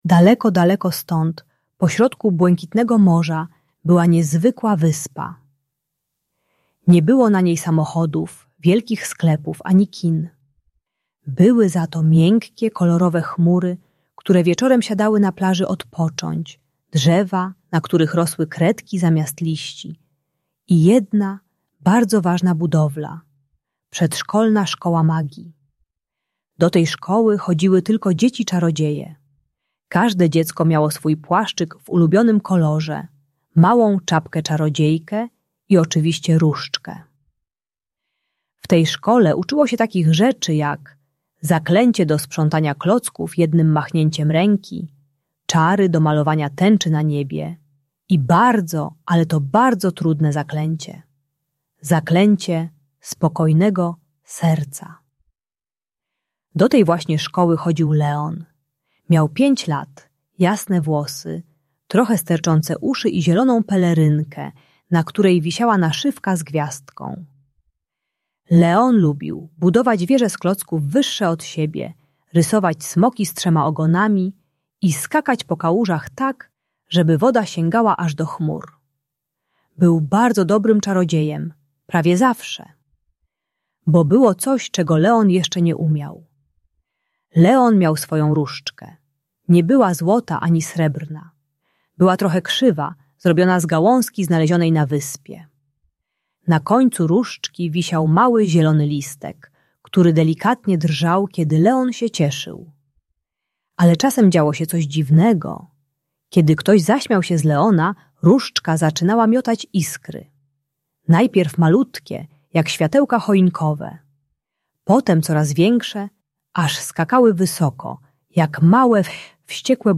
Magiczna historia Leona - Przedszkole | Audiobajka